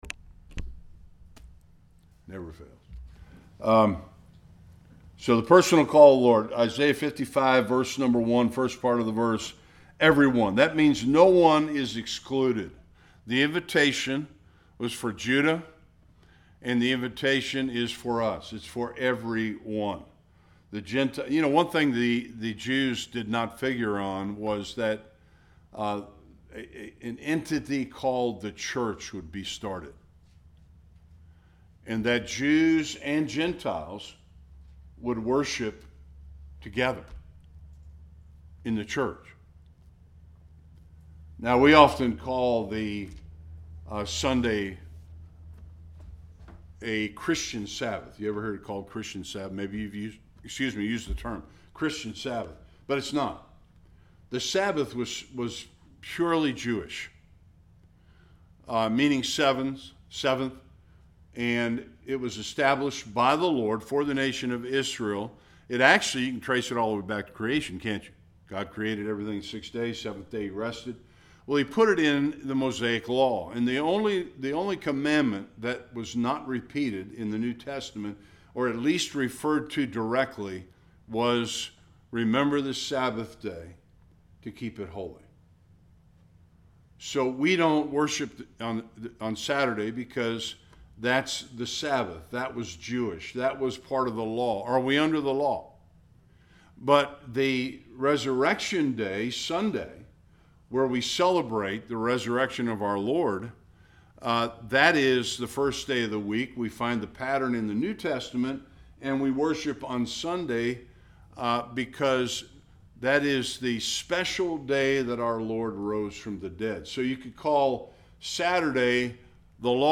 1-13 Service Type: Bible Study One of the most beautiful and gracious invitations recorded in Scripture.